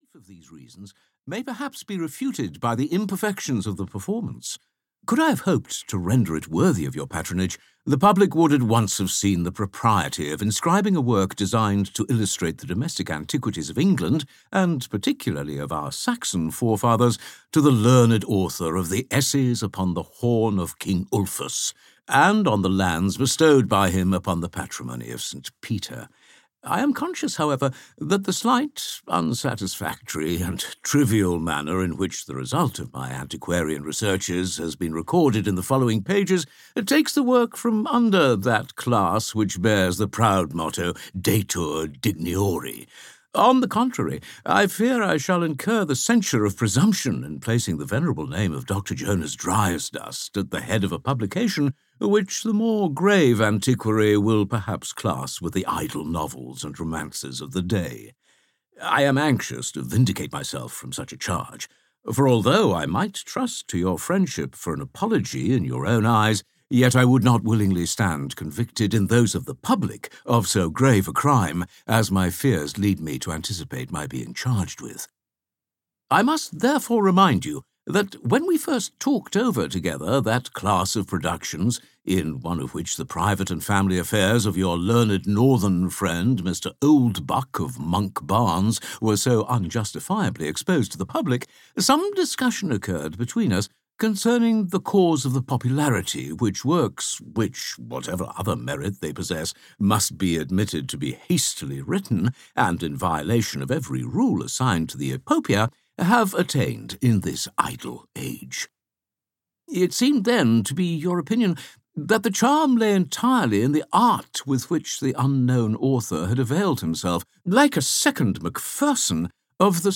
Ivanhoe (EN) audiokniha
Ukázka z knihy